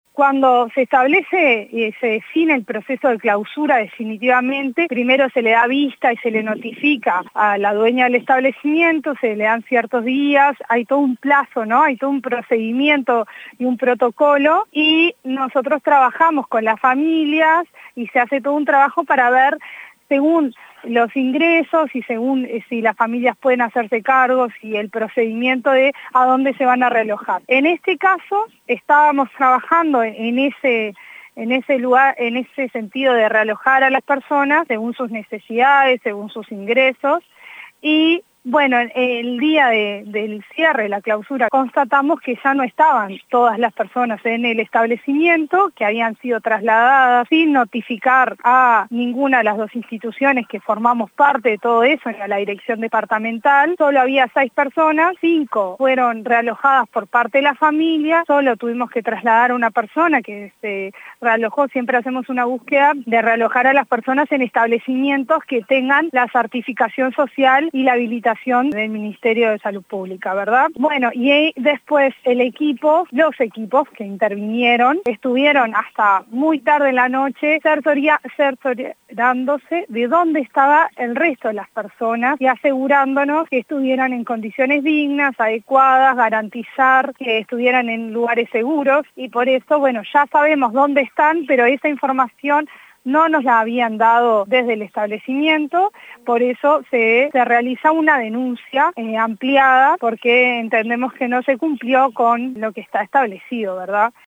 La directora del Instituto Nacional de las Personas Mayores, Marianela Larzábal, detalló el trabajo que lleva adelante desde la cartera.